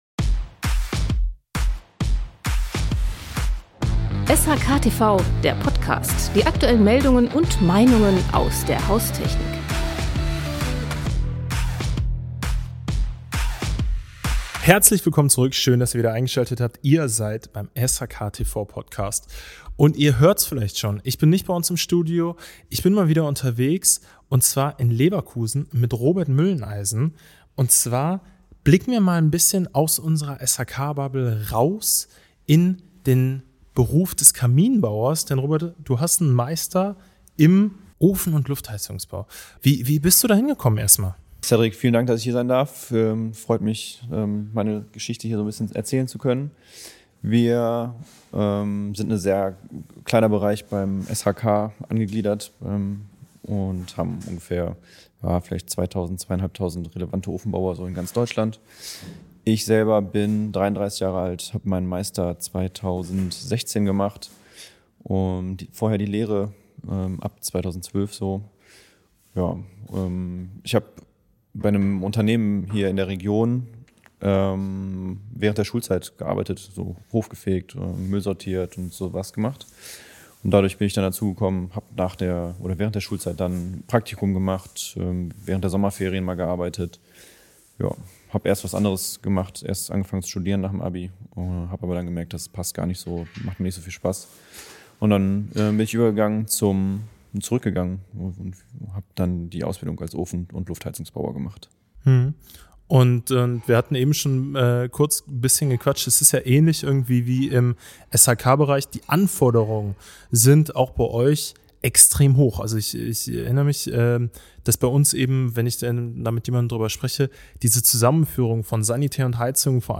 Was braucht es, damit der Kaminofen auch morgen noch brennt – wirtschaftlich wie ökologisch? Ein Gespräch über Leidenschaft im Handwerk, den Wandel im Markt und den Mut zur Meinung.